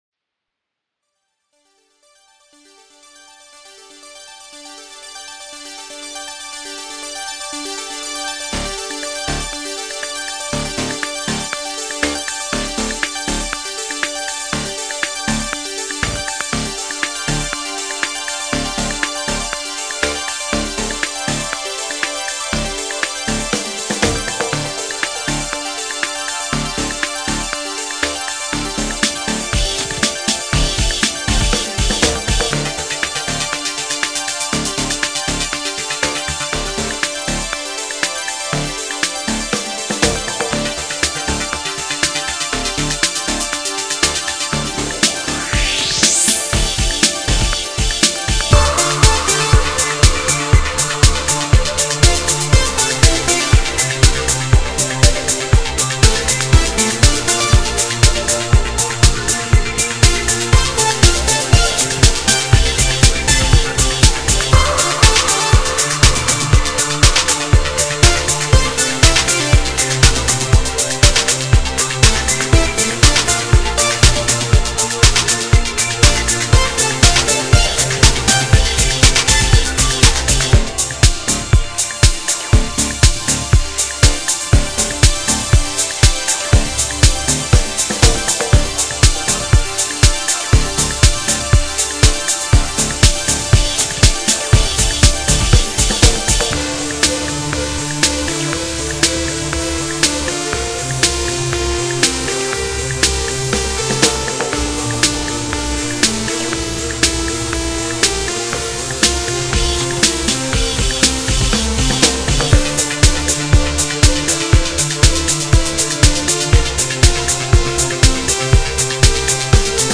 Ich mache hauptsächlich elektronische, instrumentale Musik.